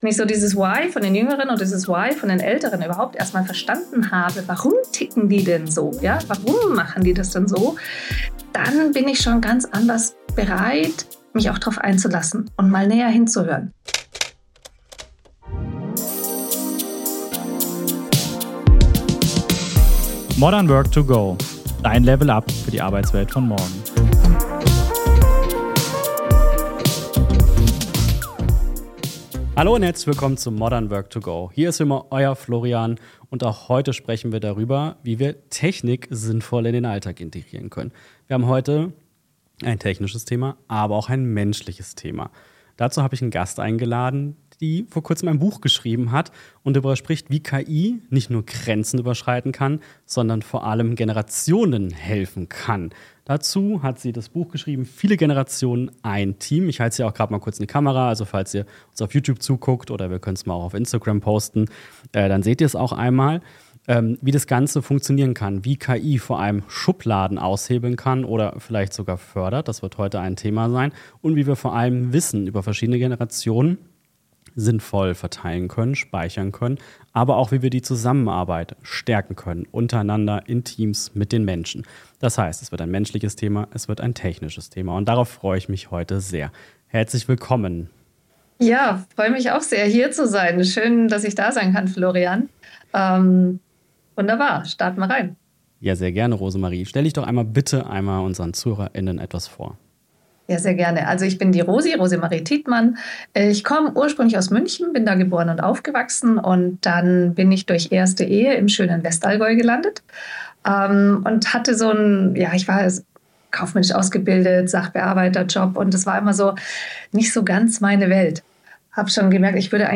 #99 Wie verändert KI den Generationskonflikt? - Interview